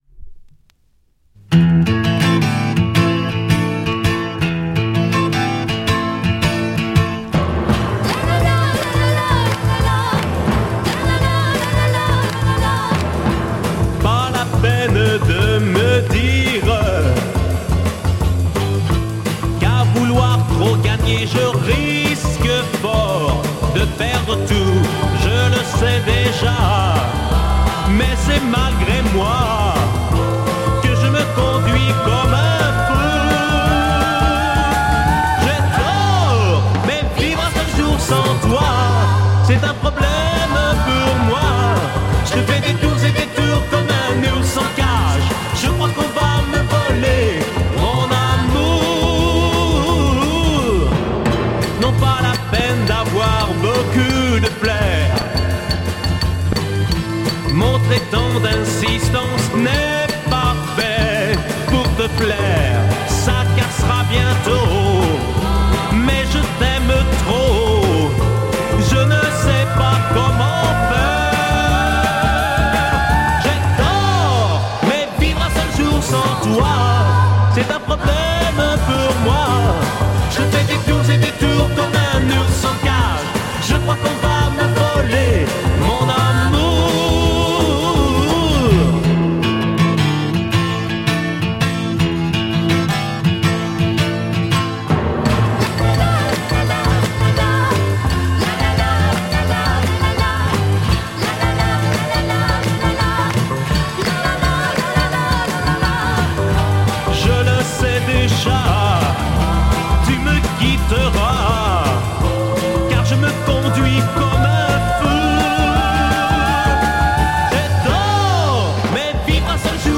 orch backing
French cover version.